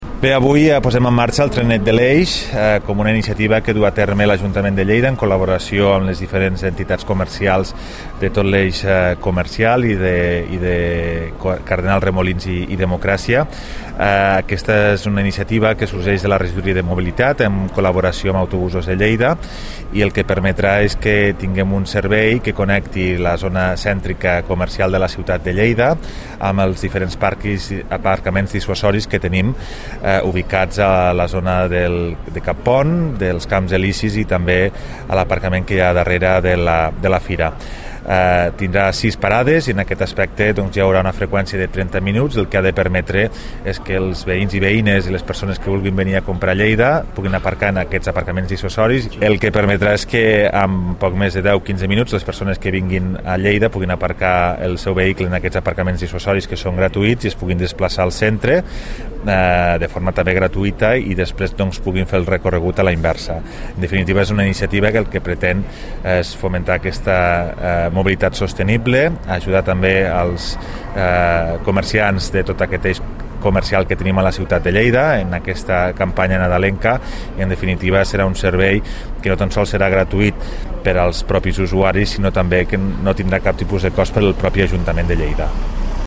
tall-de-veu-toni-postius